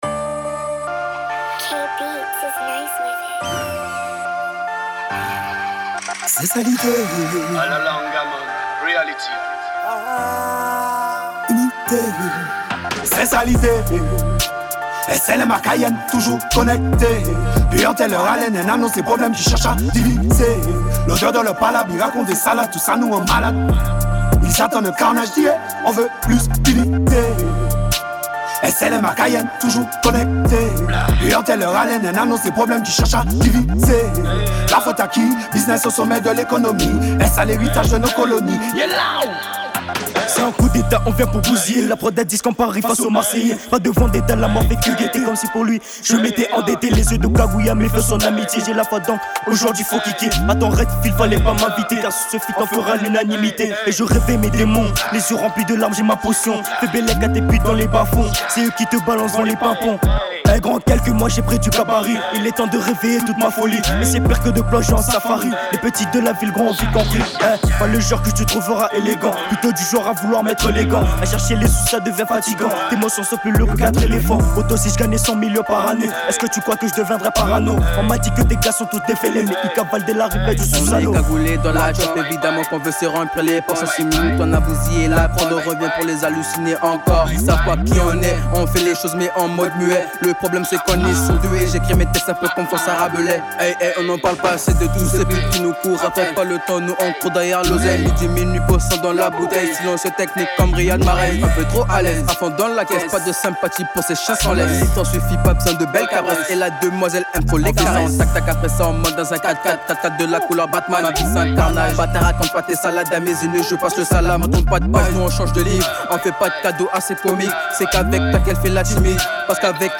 Drill